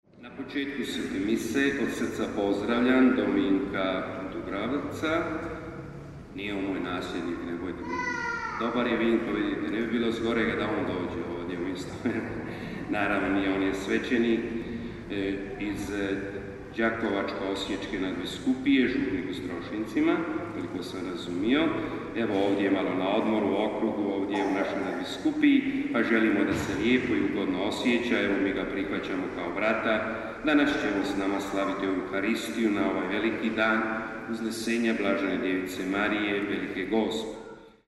Svetkovina UZNESENJA BDM VELIKA GOSPA, mjesto događanja Župna Crkva.
POČETAK MISNOG SLAVLJA: